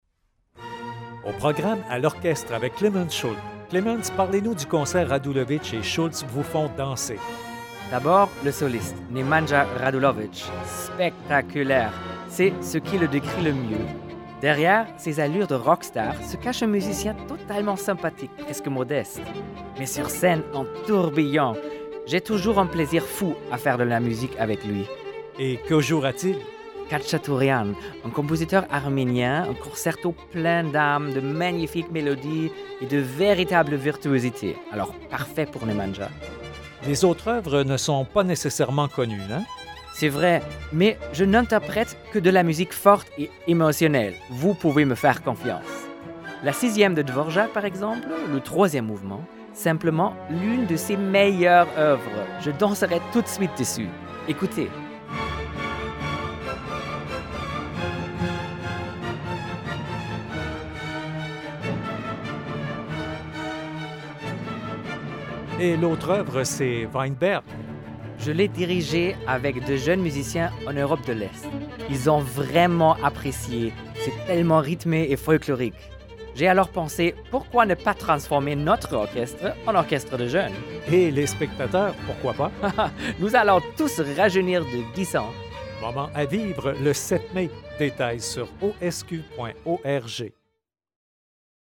Clemens Schuldt, chef Nemanja Radulović, violon